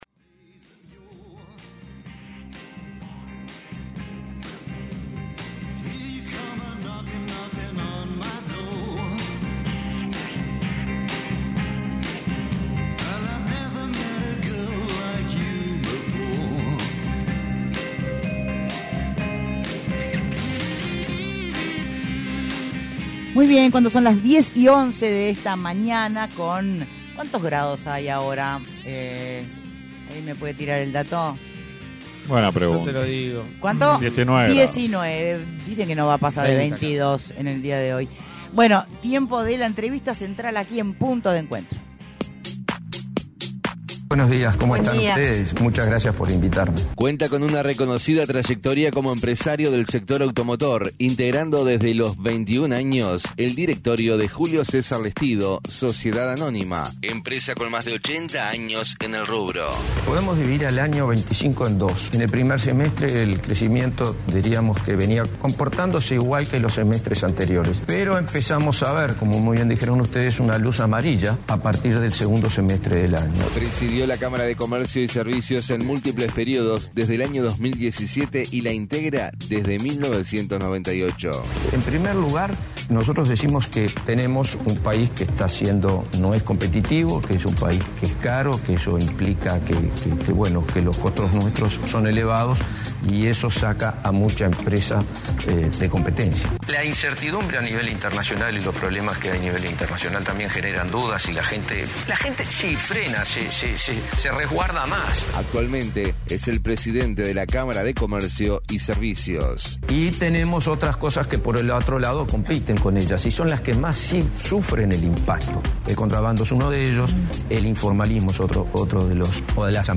ENTREVISTA: